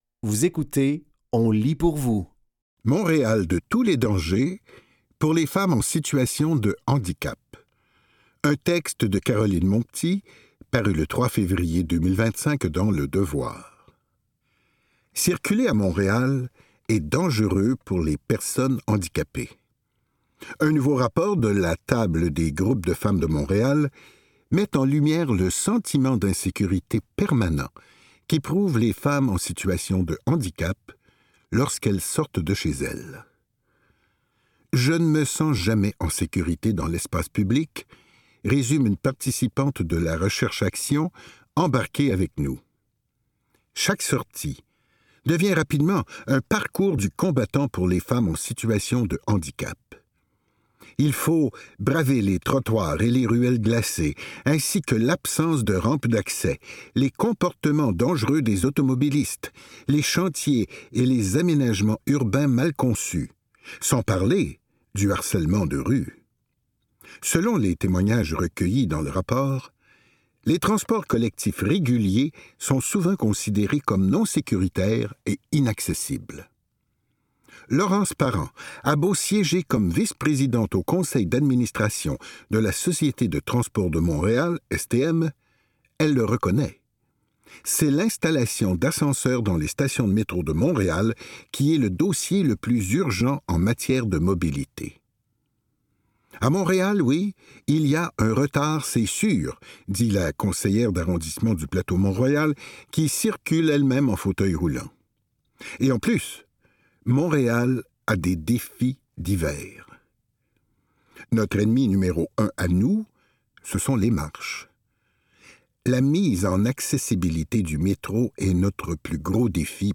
Dans cet épisode de On lit pour vous, nous vous offrons une sélection de textes tirés des médias suivants: Le Devoir, La Presse et ICI Manitoba.